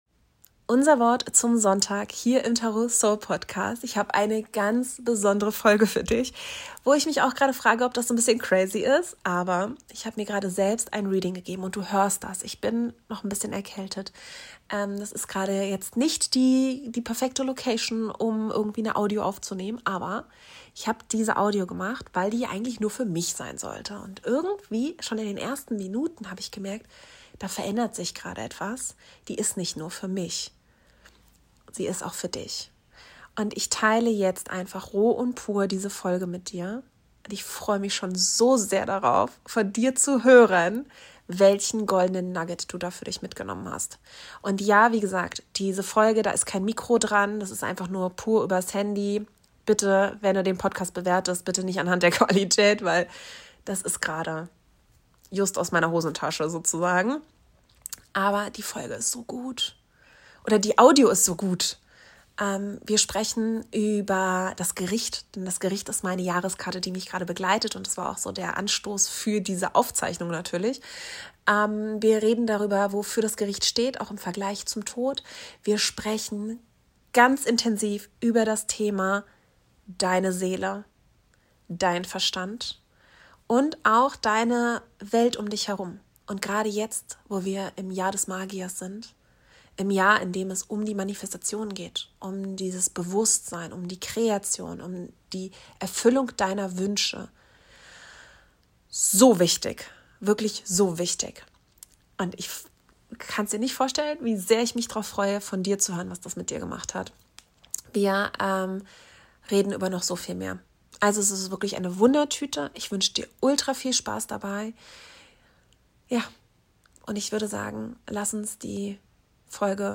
Diese Folge ist intuitiv, ungefiltert und direkt aus dem Moment entstanden - ein High Priestess Talk über Erwache, Verantwortung und dein volles Potenzial.